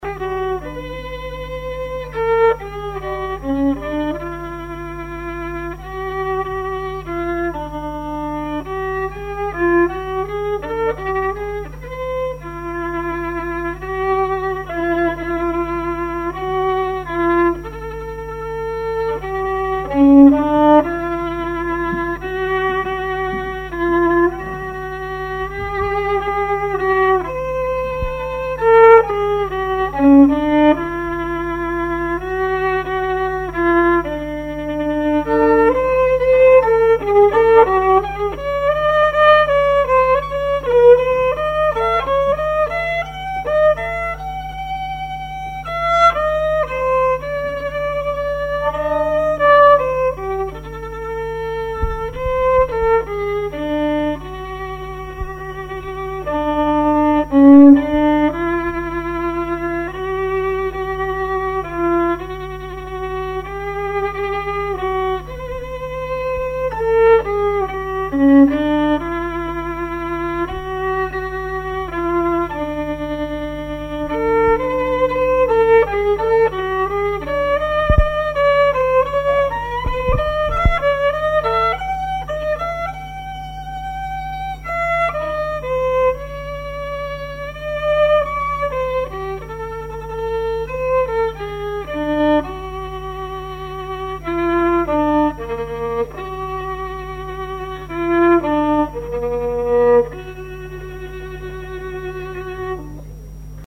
Genre brève
collectif de musiciens pour une animation
Pièce musicale inédite